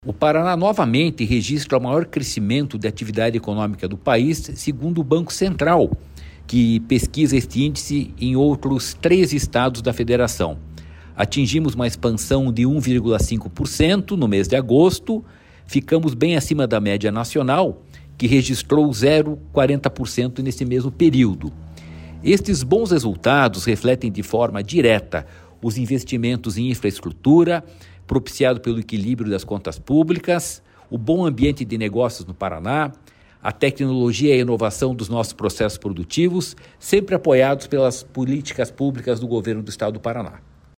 Sonora do presidente do Ipardes, Jorge Callado, sobre o crescimento da atividade econômica do Estado